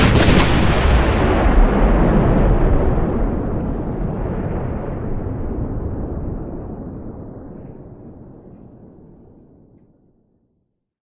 shellexplode.ogg